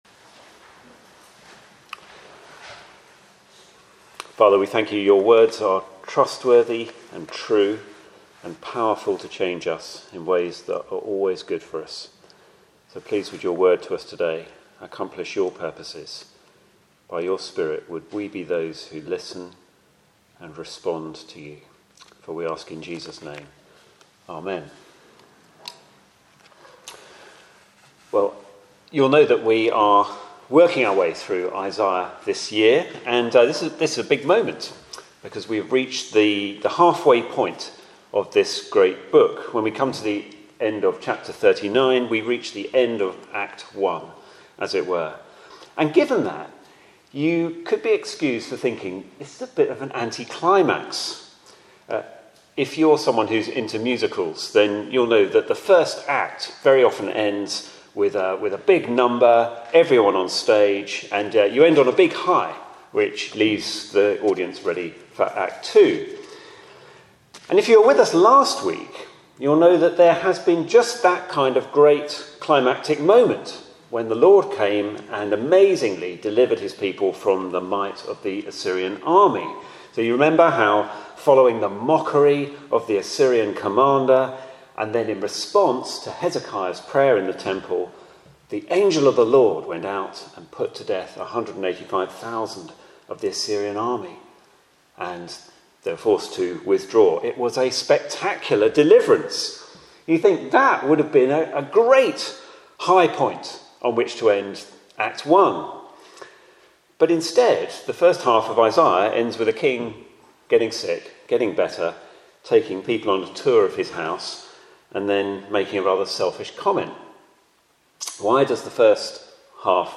Media for Sunday Evening on Sun 12th Feb 2023 18:00